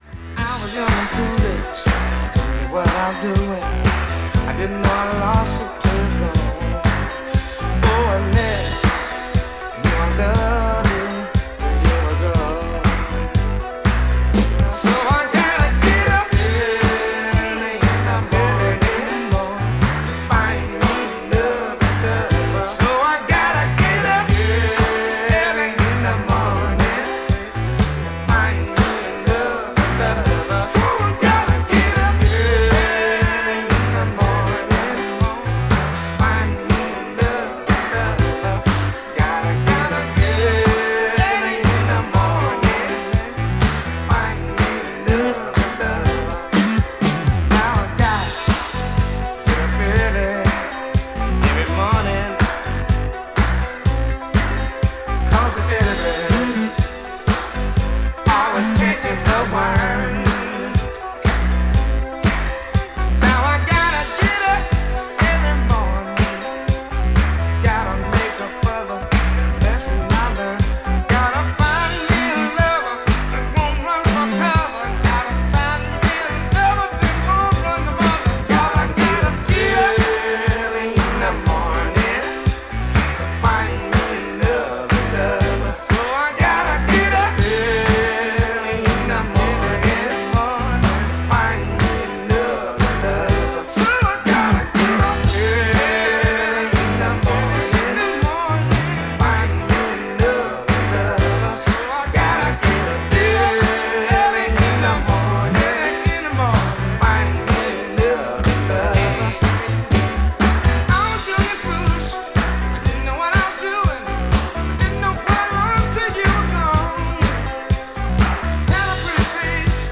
STYLE Disco / Boogie